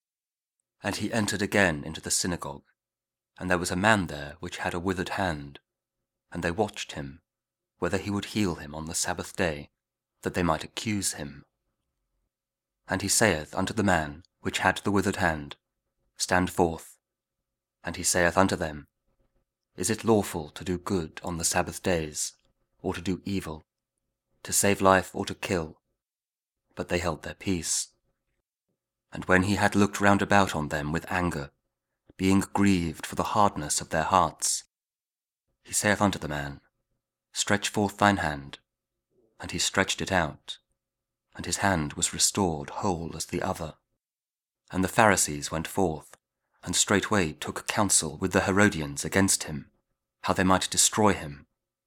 Mark 3: 1-6 – Week 2 Ordinary Time, Wednesday (Audio Bible KJV, Spoken Word)